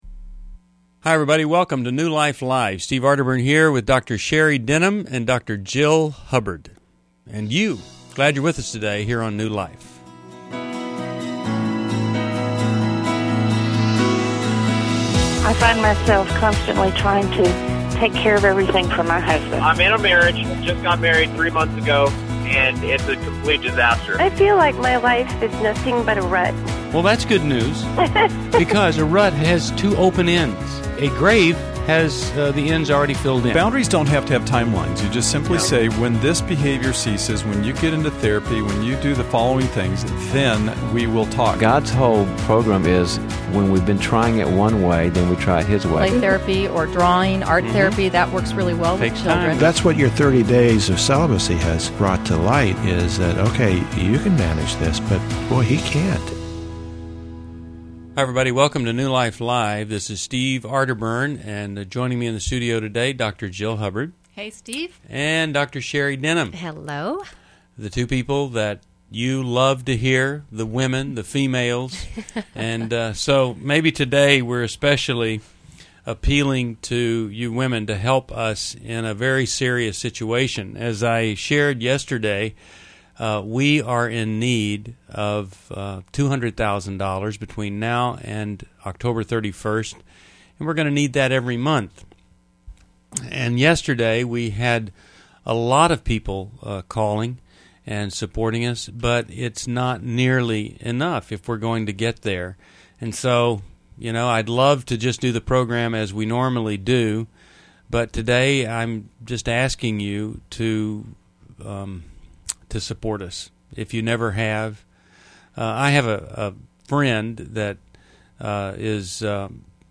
Caller Questions: 1. My fiance is a pastor going to EMB; should he step down? 2.